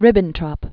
(rĭbən-trŏp, -trôp), Joachim von 1893-1946.